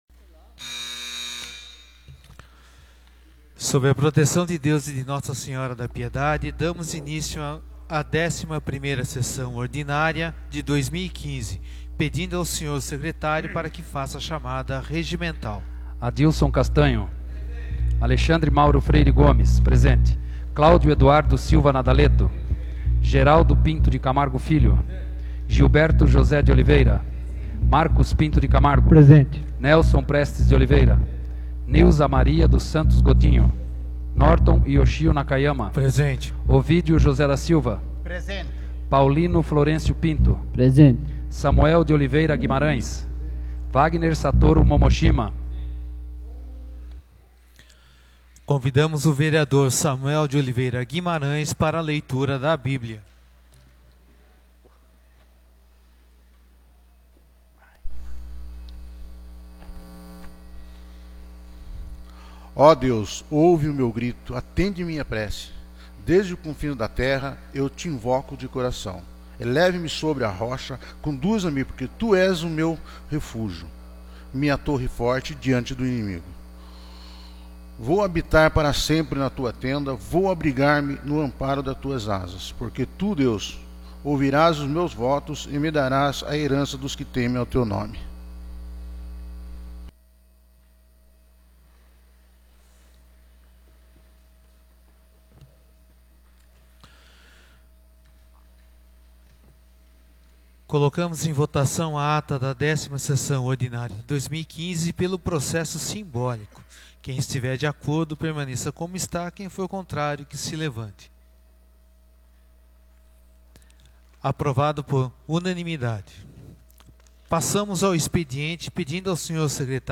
11ª Sessão Ordinária de 2015